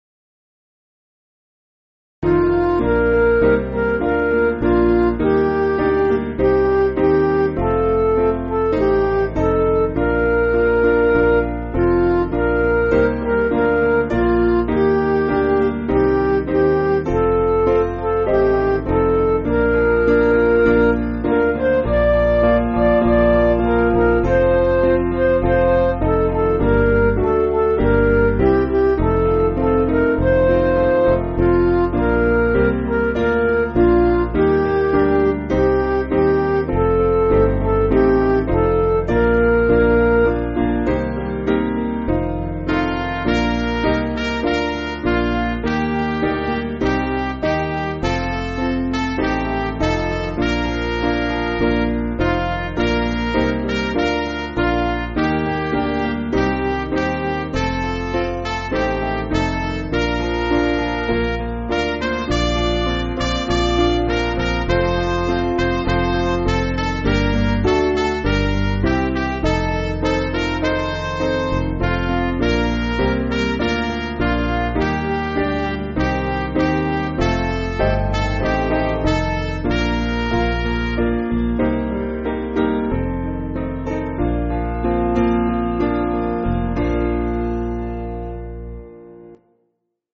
Piano & Instrumental
(CM)   2/Bb